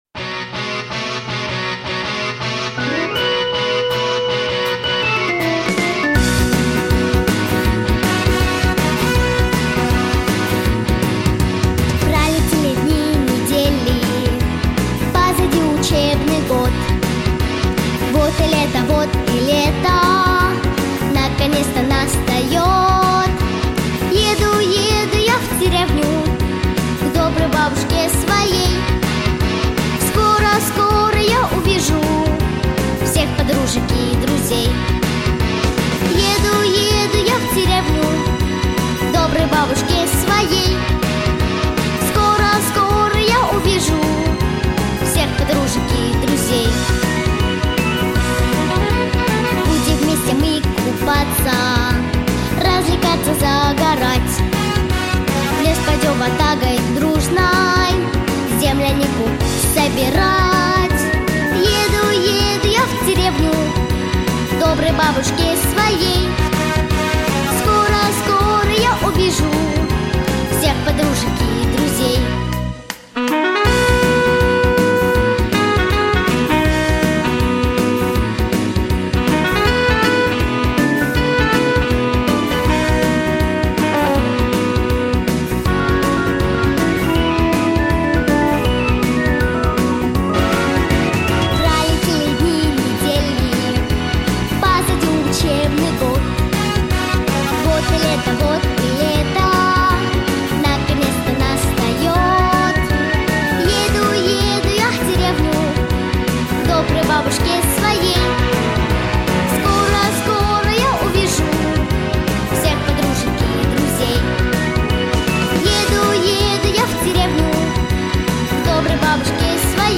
• Категория: Детские песни / Песни про бабушку